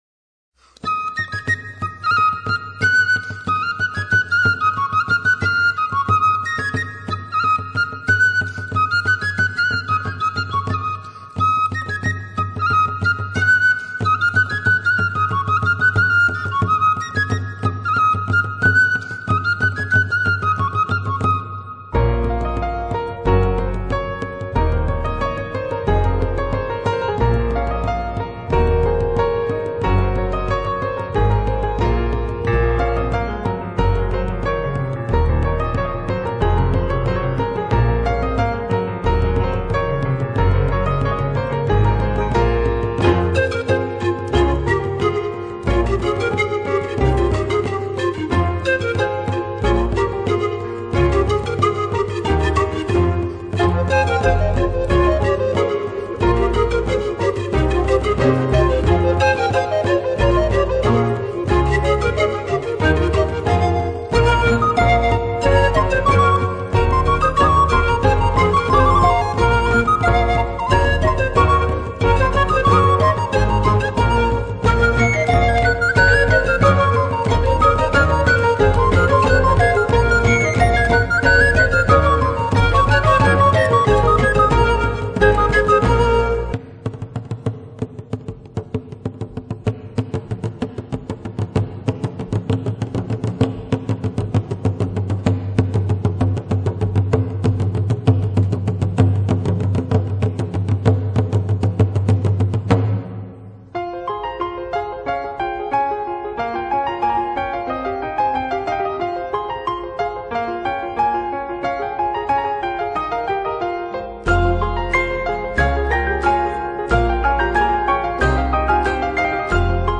钢琴与排笛的精彩乐器对话，营造出非常缥缈浪漫，轻盈的旋律，带我们感受生命的气息，触摸生命的跳动……